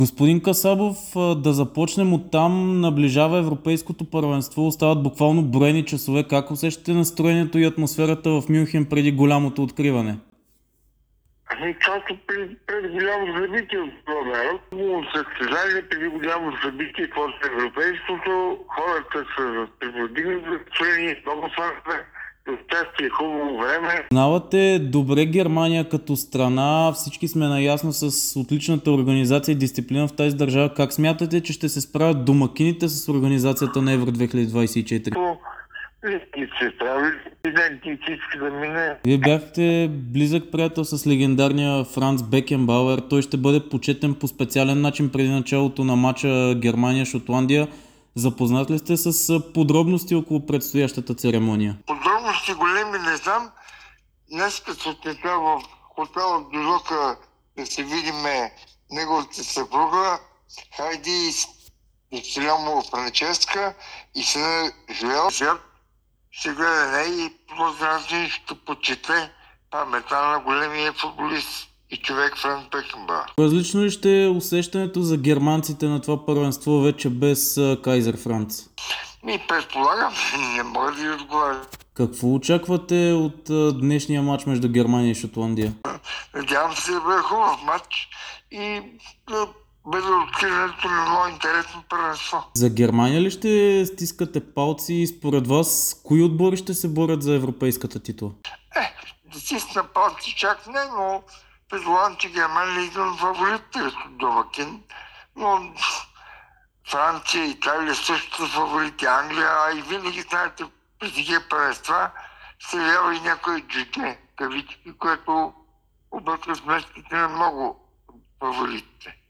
даде интервю за dsport и Дарик радио часове преди старта на Евро 2024.